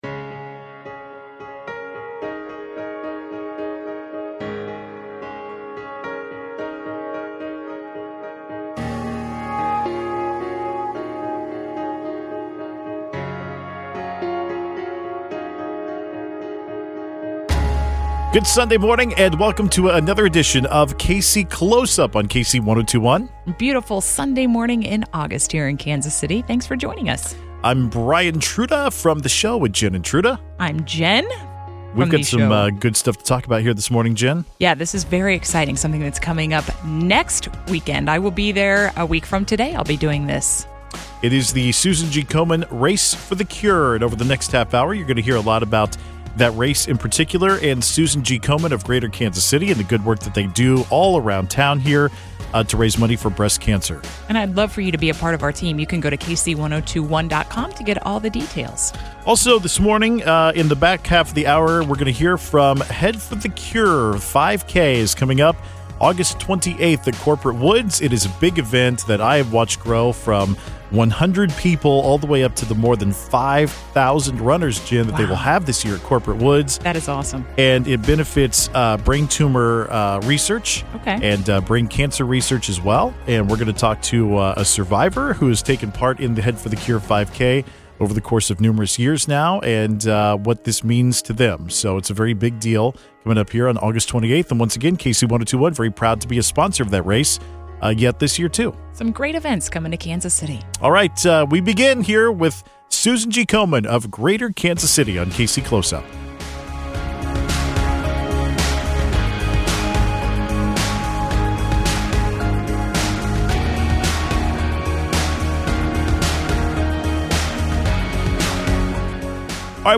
KC102.1's Public Affairs show